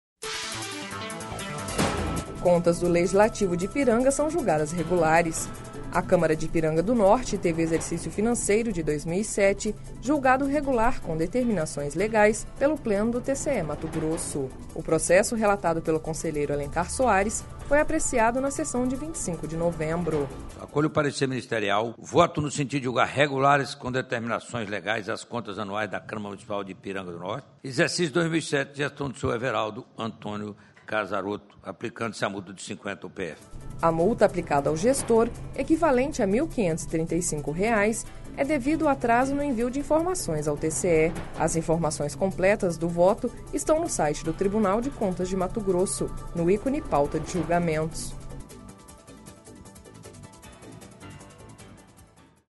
Sonora : Alencar Soares – conselheiro do TCE-MT